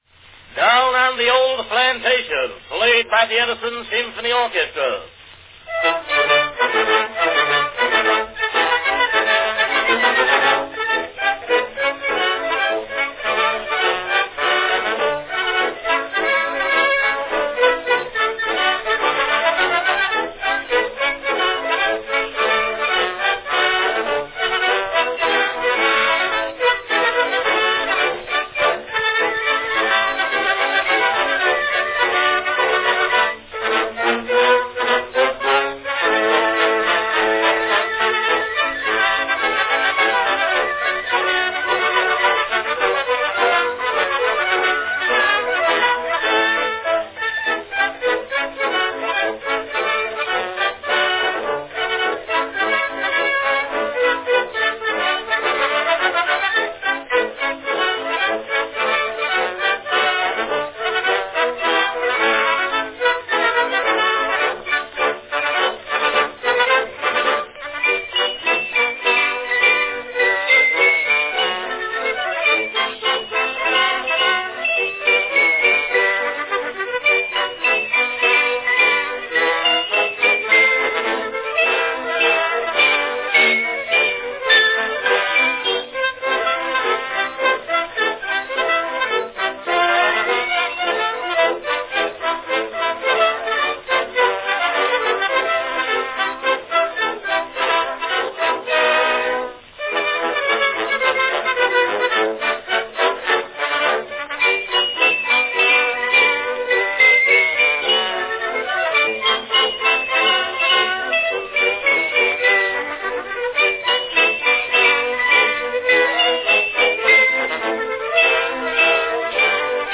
Cylinder of the Month
Category Orchestra
Performed by Edison Symphony Orchestra
Announcement "Down on the Old Plantation, played by the Edison Symphony Orchestra."
In this recording, you can hear the Stroh's squall especially in the first few measures of the main theme.
This particular recording is apparently a circa 1904 re-make of the original September 1902 release.